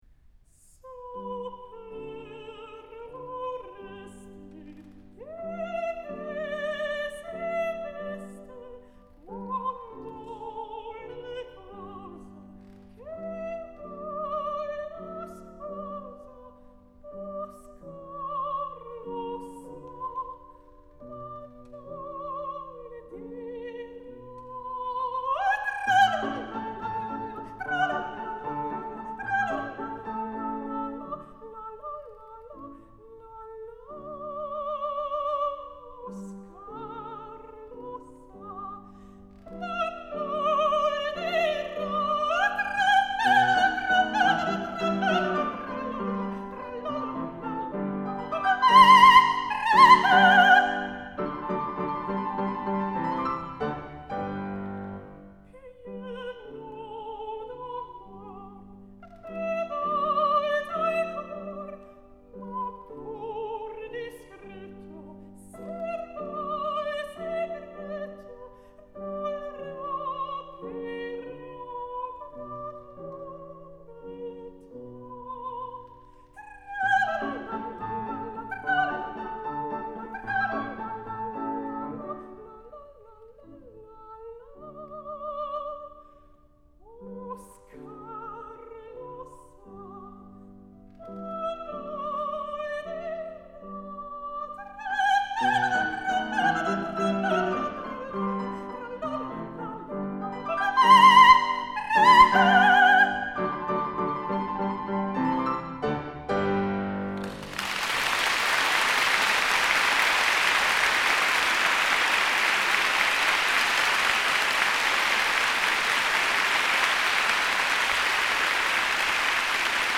Boy Soprano
Piano